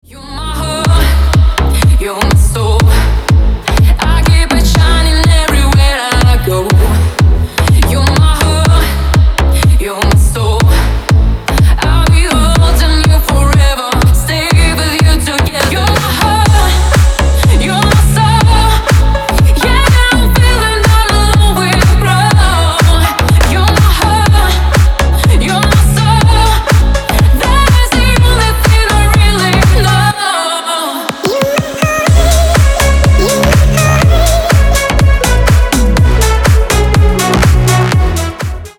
Поп Музыка
клубные # громкие